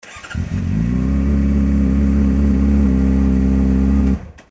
モリワキマフラーのアイドリング音(一次側のみ消音)
ほぼ純正マフラーと同じ音圧になりました。